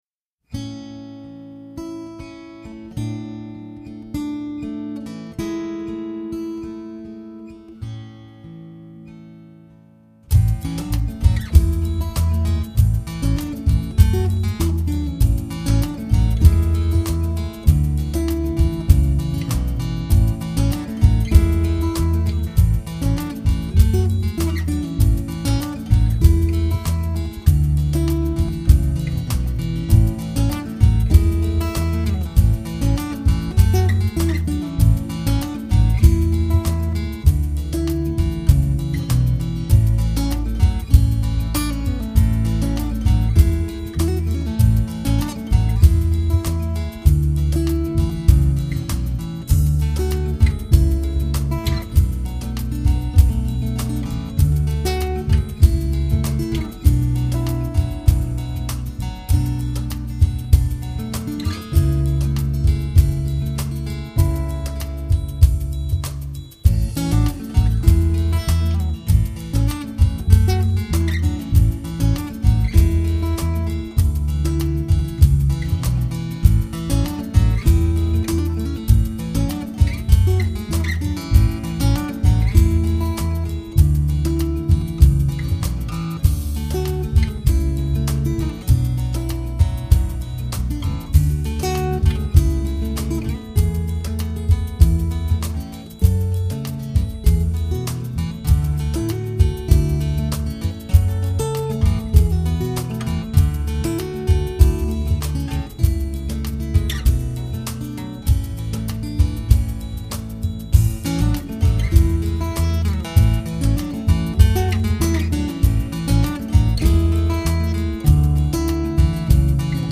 专辑风格：New Age，Guitar
声学吉他，长笛，和塔布拉，带你到时间的另一个地方轻松又宁静，而同时充满了令人振奋的幸福和快乐…！